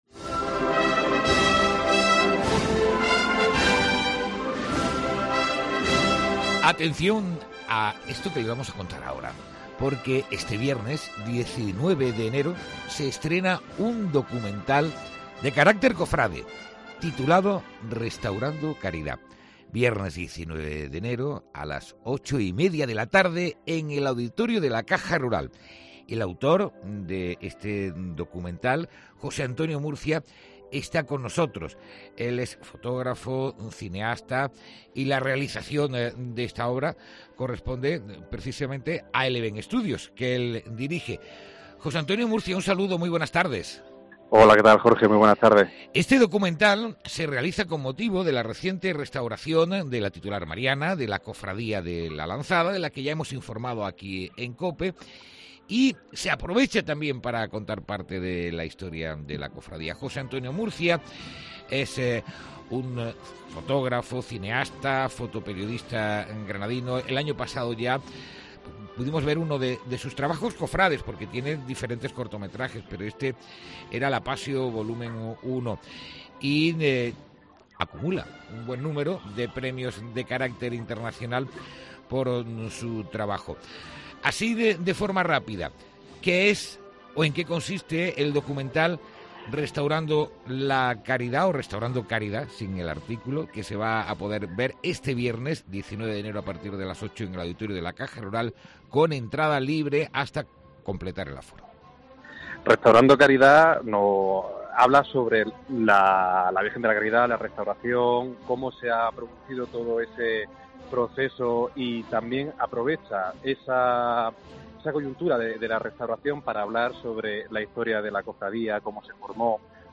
ENTREVISTA||Este viernes se estrena el documental de la Cofradía de la Lanzada "Restaurando Caridad"